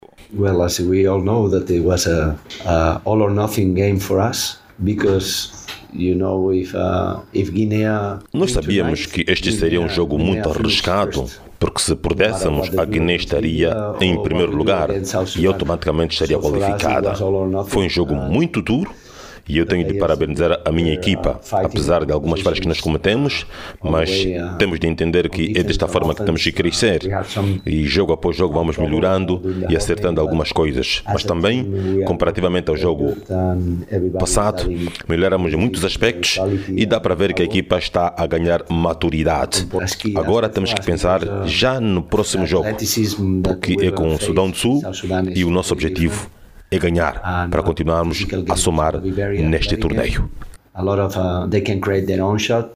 O técnico Pepe Clarós destacou o desempenho do cinco nacional, sublinhando a evolução apresentada ao longo da partida.